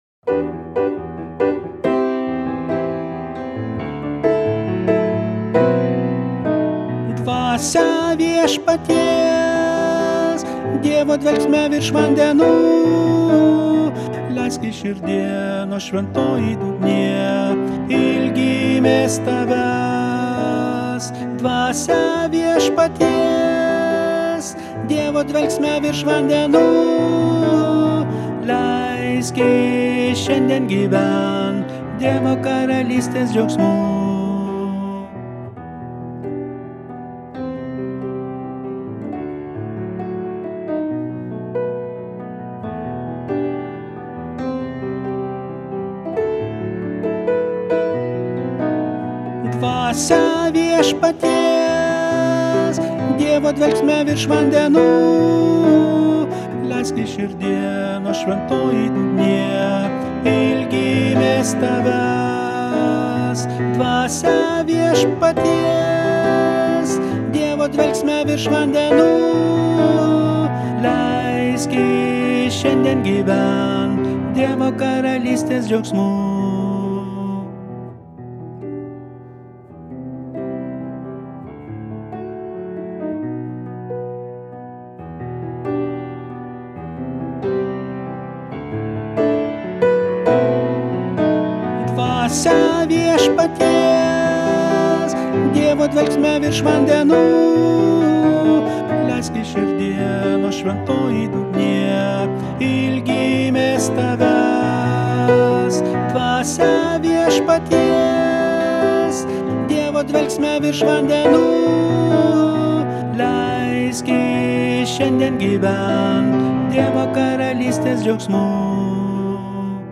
Tenoras: